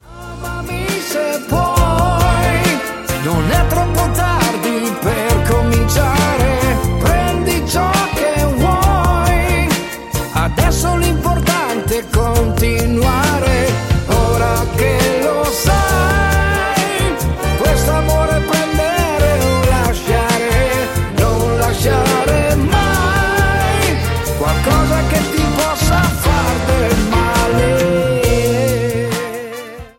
SLOW DUINE  (03,42)